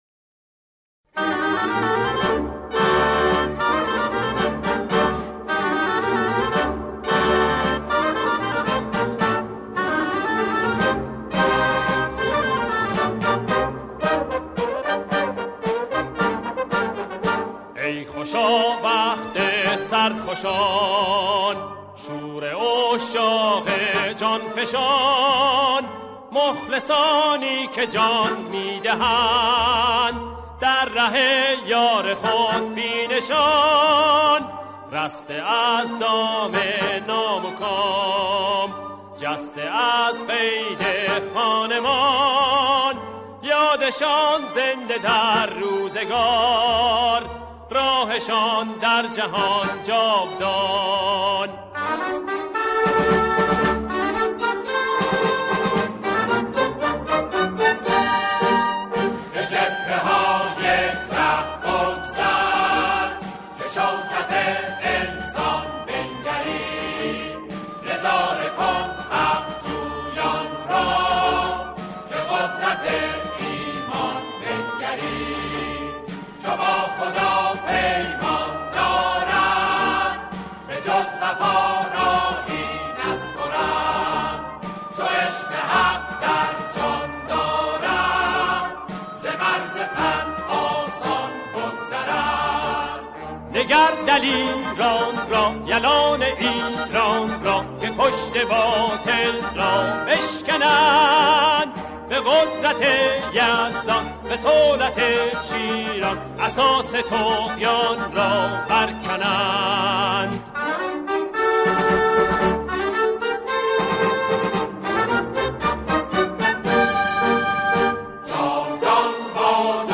آنها در این قطعه، شعری را درباره دفاع مقدس همخوانی می‌کنند.